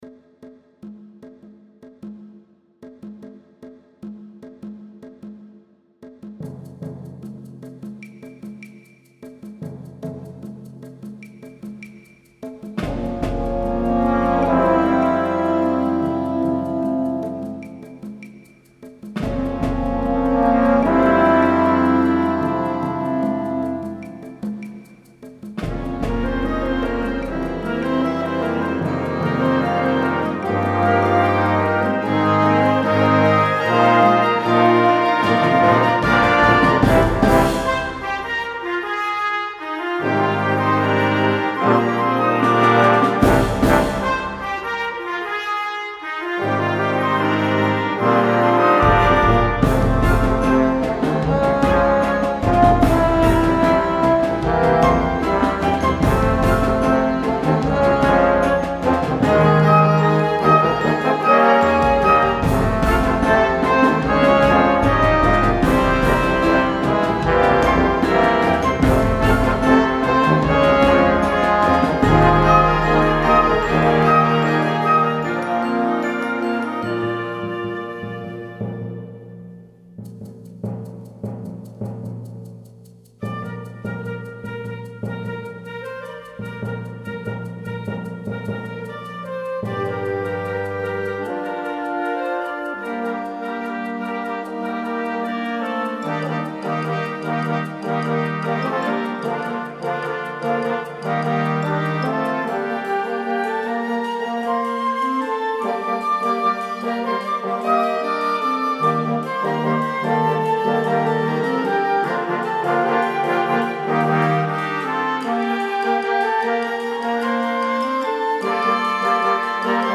Genre: Band
Mallets (glockenspiel, xylophone, shaker)
Percussion 1 (snare drum, bass drum)
Percussion 2 (crash cymbals, suspended cymbal, wind chimes)